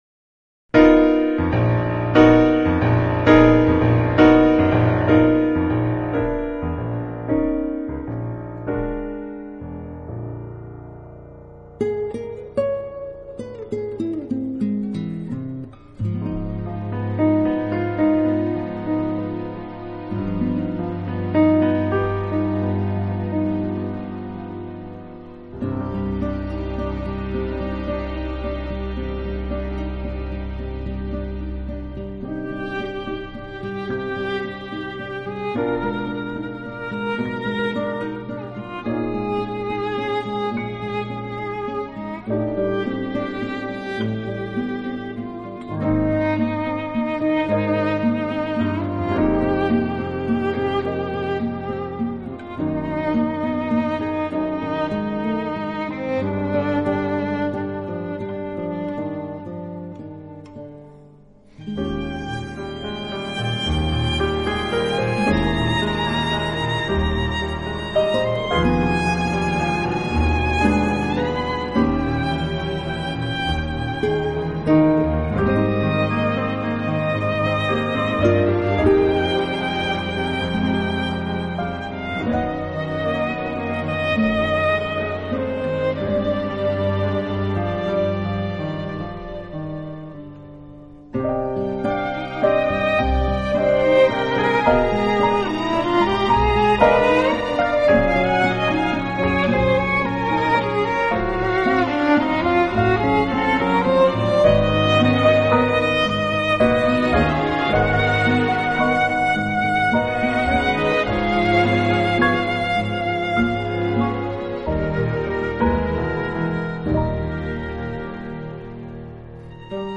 通过清新时尚的创作、配器和演绎，把古典音乐以很舒服的方式呈现
violin